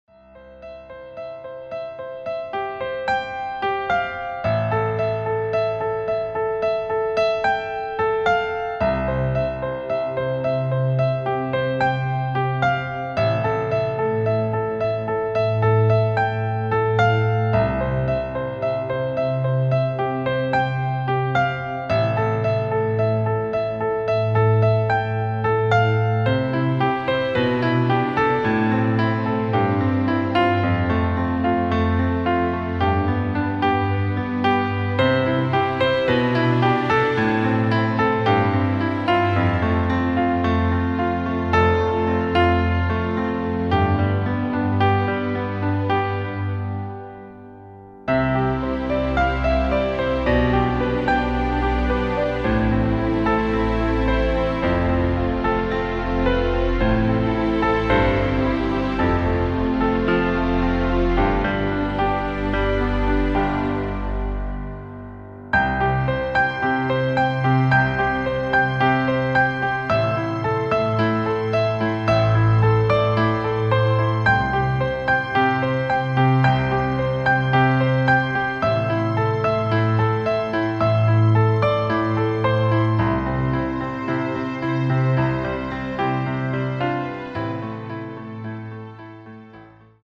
• Tonart:  C Dur, D Dur
• Art: Klavier Streicher Version
• Das Instrumental beinhaltet NICHT die Leadstimme
Klavier / Streicher